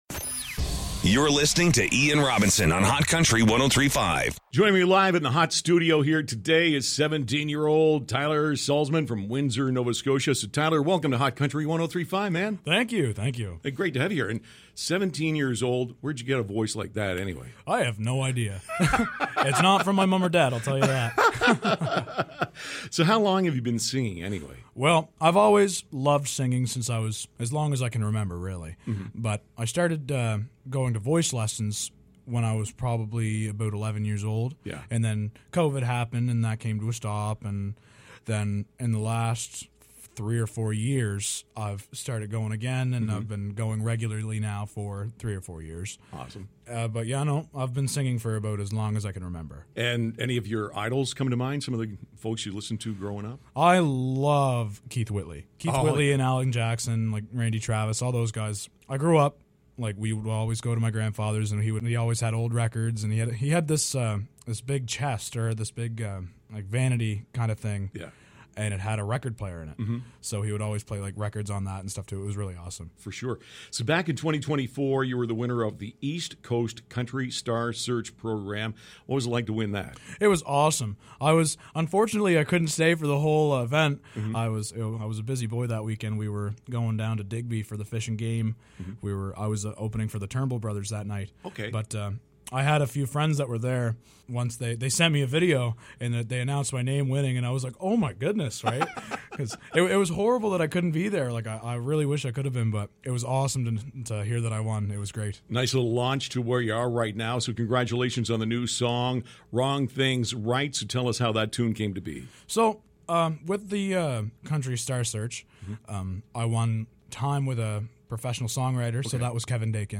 Have a listen to our chat below and find out more, plus who his music idols were growing up and how he’s giving back to the community.